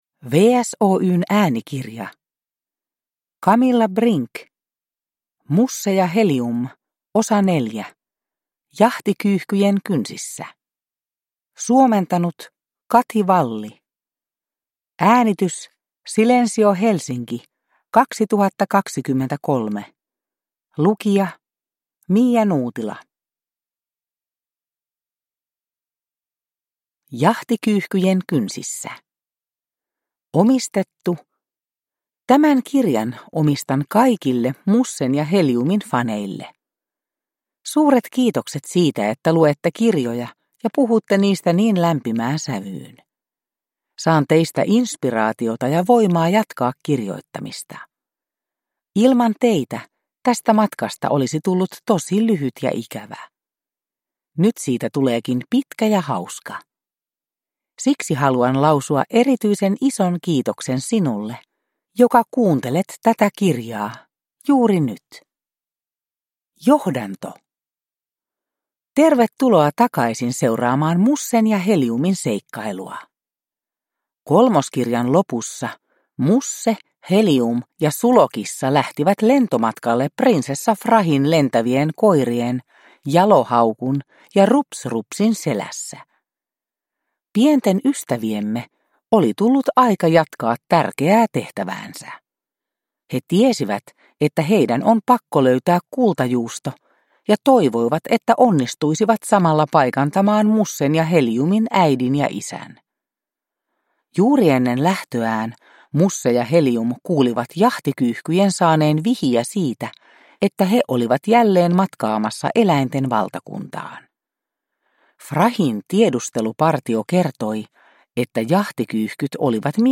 Musse ja Helium 4: Jahtikyyhkyjen kynsissä – Ljudbok – Laddas ner